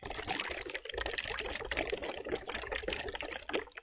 人类的，冒泡的
描述：通过稻草在饮料中制造泡沫。一些冰。使用CA桌面麦克风录制。
Tag: 不成熟 气泡 气泡 人类 不礼貌 鼓泡 粗鲁